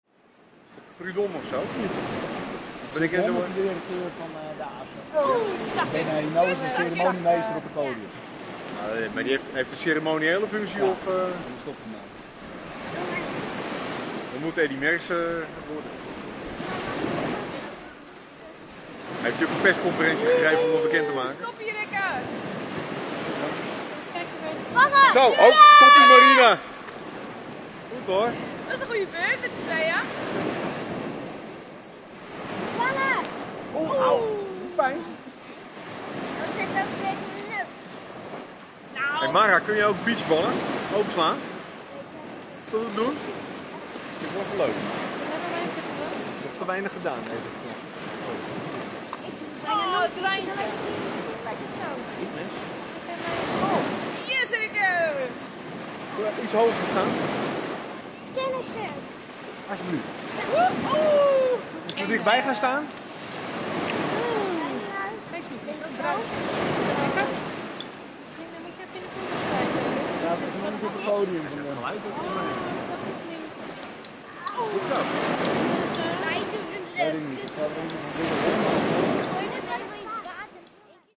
描述：一位动画师在海边拍摄两个孩子。
标签： 儿童 海滩 卡拉 奥罗塞伊 Ginepro 摄影师 撒丁岛
声道立体声